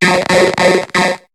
Cri de Krabby dans Pokémon HOME.